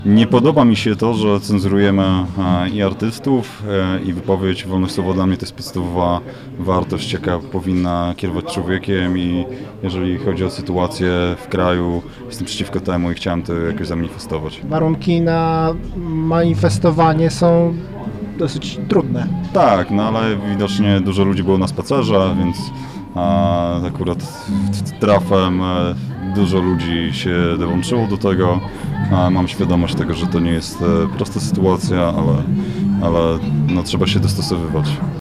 w rozmowie z reporterem Radia 5 podkreślił